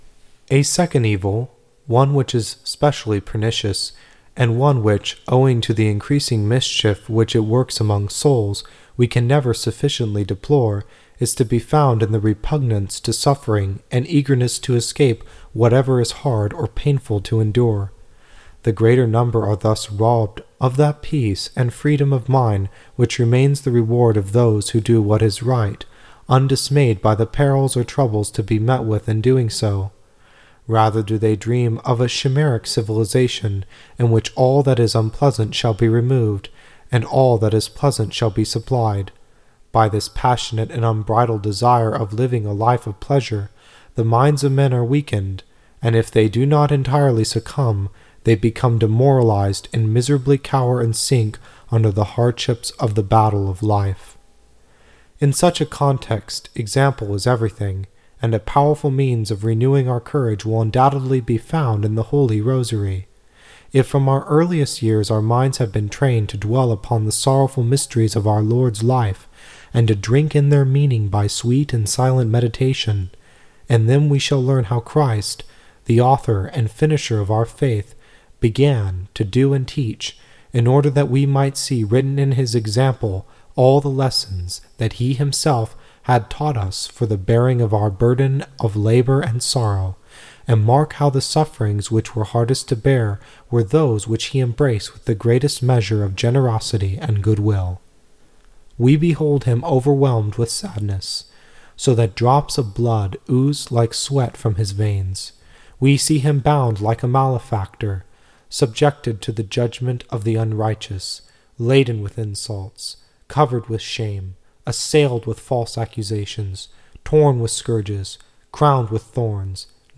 Click Here to Download the Entire AudioBook The Holy Rosary Remedy Against Modern Evils.